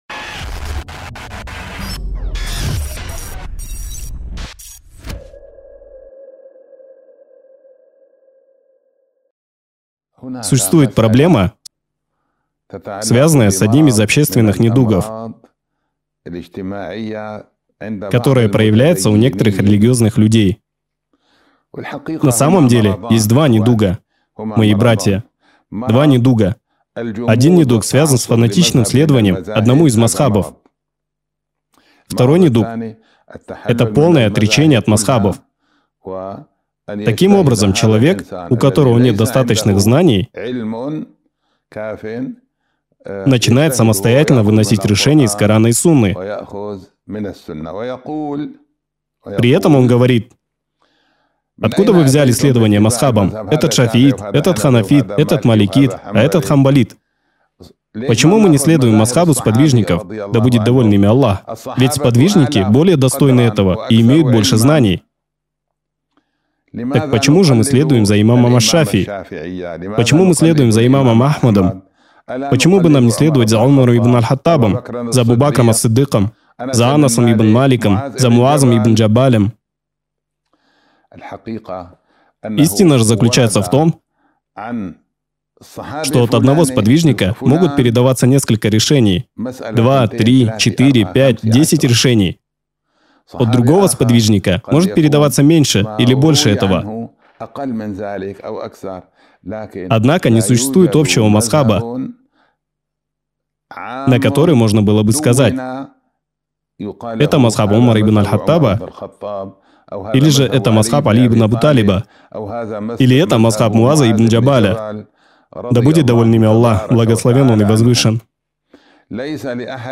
Лектор